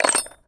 metal_small_movement_01.wav